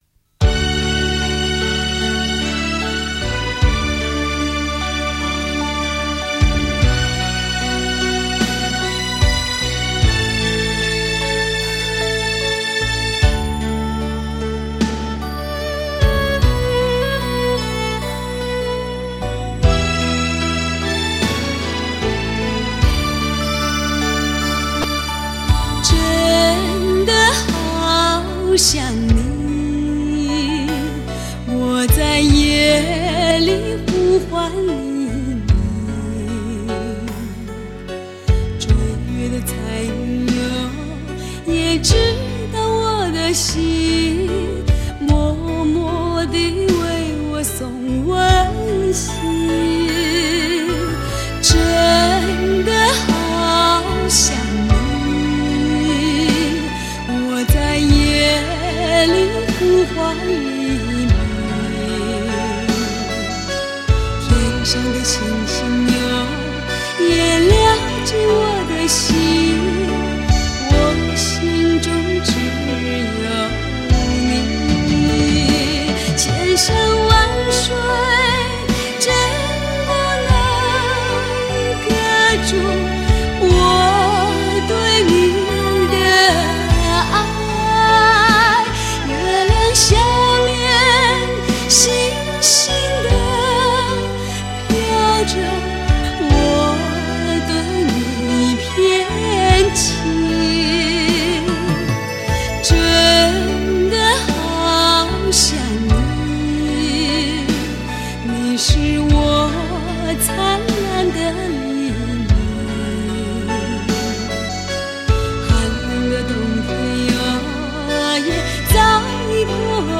VCD 转 wav